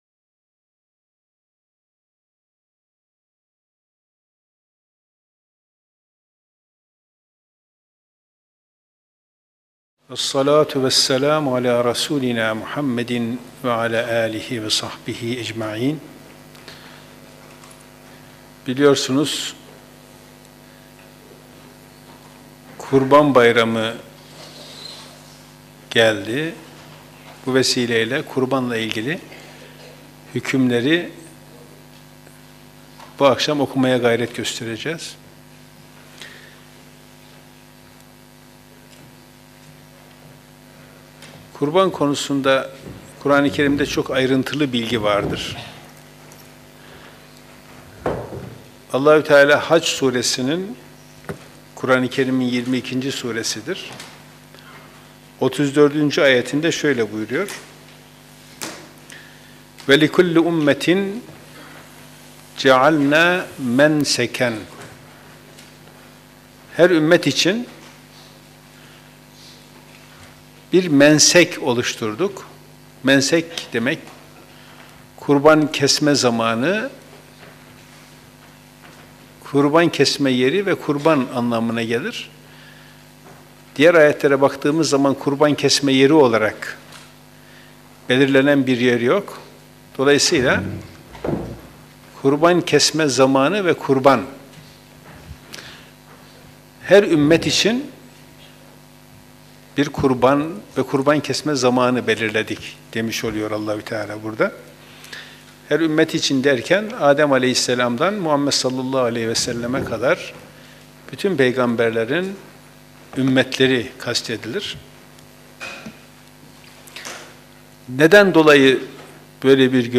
Kur'an Sohbetleri